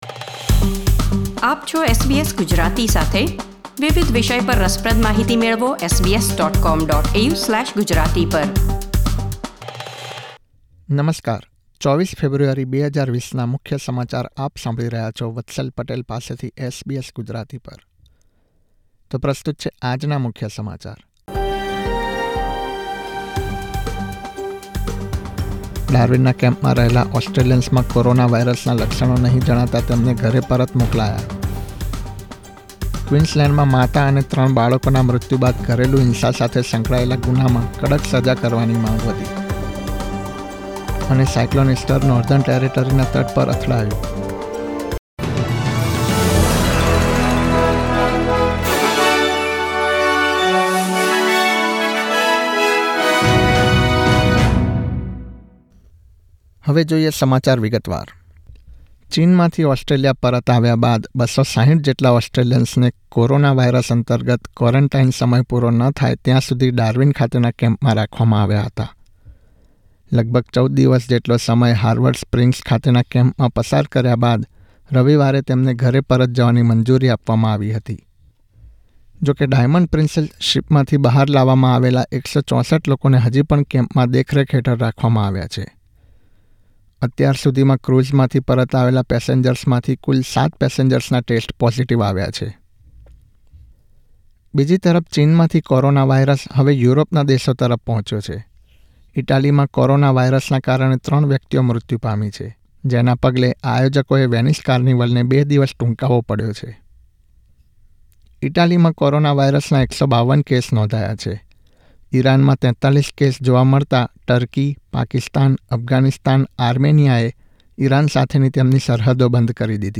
૨૪ ફેબ્રુઆરી ૨૦૨૦ના મુખ્ય સમાચાર